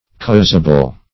Causable \Caus"a*ble\, a.
causable.mp3